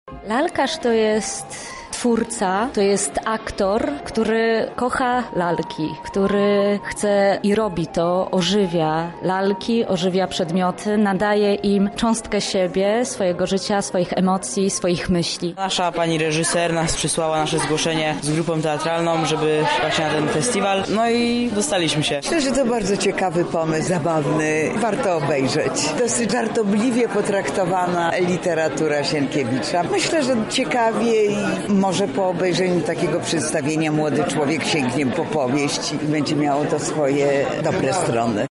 Miłośnicy i twórcy teatru lalek gościli na zjeździe w Lublinie.